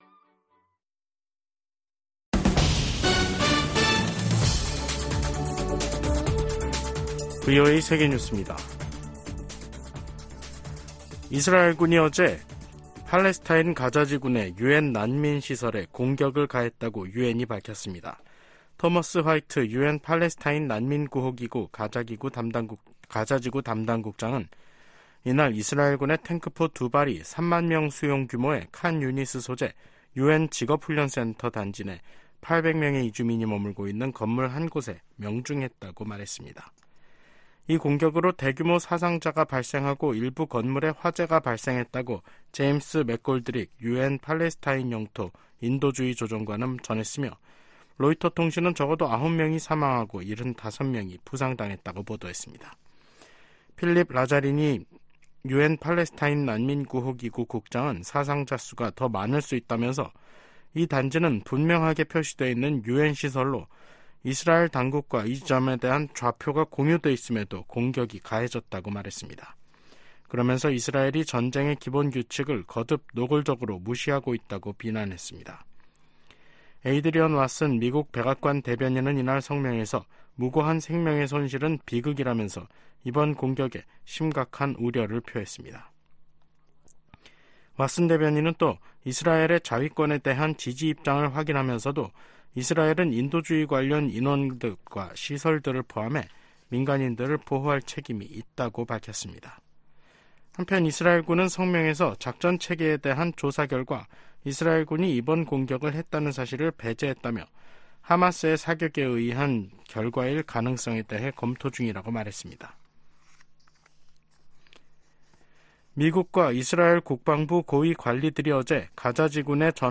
세계 뉴스와 함께 미국의 모든 것을 소개하는 '생방송 여기는 워싱턴입니다', 2024년 1월 25일 저녁 방송입니다. '지구촌 오늘'에서는 라이칭더 타이완 총통 당선인이 타이베이에서 미 하원 대표단을 만나 미국의 지속적인 지지를 바란다고 밝힌 소식 전해드리고, '아메리카 나우'에서는 대선이 조 바이든 대통령과 도널드 트럼프 전 대통령의 재대결로 펼쳐질 가능성이 높아진 이야기 살펴보겠습니다.